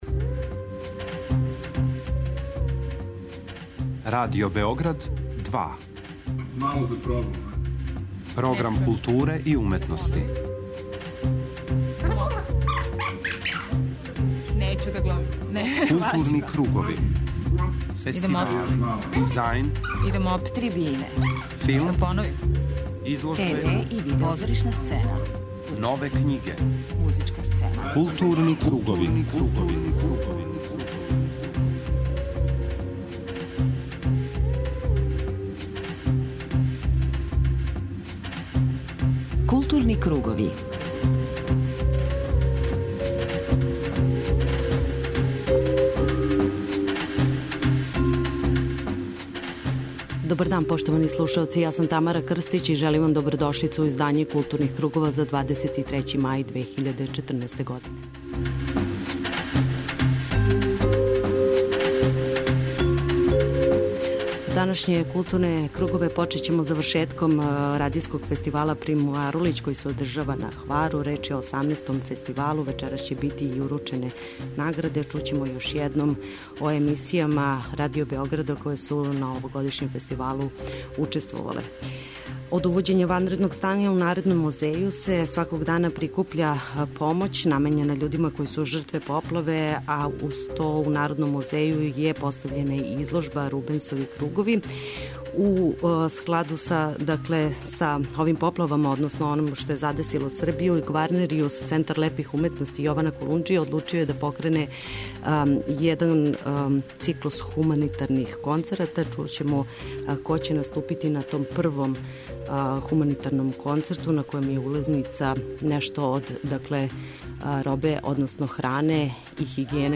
преузми : 54.23 MB Културни кругови Autor: Група аутора Централна културно-уметничка емисија Радио Београда 2.